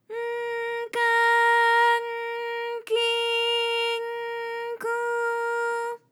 ALYS-DB-001-JPN - First Japanese UTAU vocal library of ALYS.
k_N_ka_N_ki_N_ku.wav